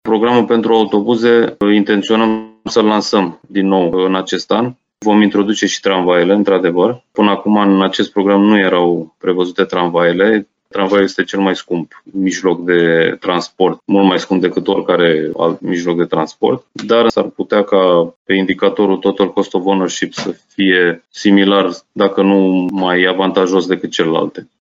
Afirmația a fost făcută la o recentă conferință on-line organizată de rețeaua CIVINET ROMÂNIA, al cărei secretariat general se află la Timișoara.
Peste 100 de specialiști din domeniul mobilității urbane, reprezentanți ai autorităților publice și ai organizațiilor din acest domeniu au participat la cele două zile de conferință on-line.